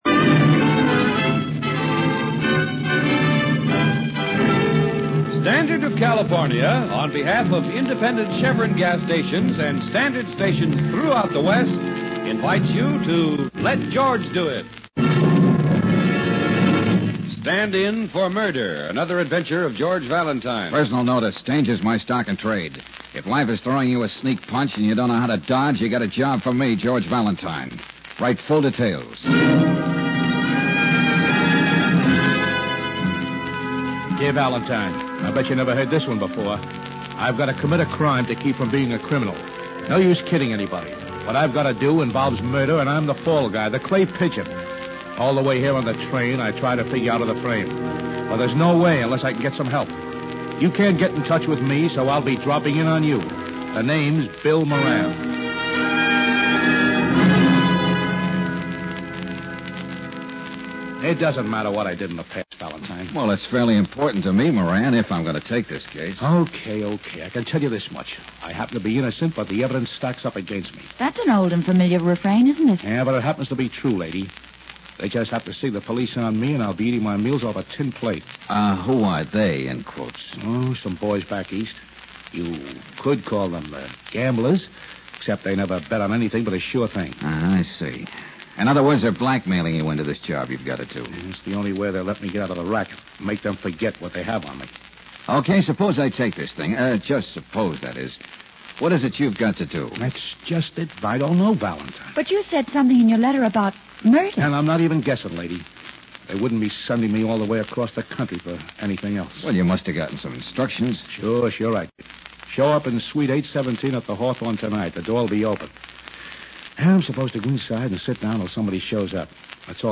Let George Do It Radio Program
Stand-in For Murder, starring Bob Bailey